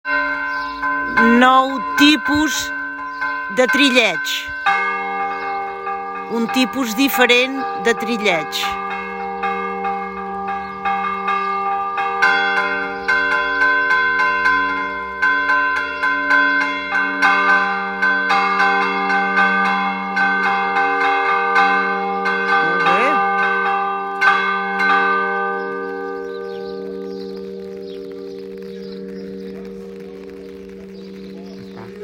LES NOSTRES CAMPANES
Trilleig.m4a